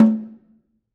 Snare2-HitNS_v5_rr1_Sum.wav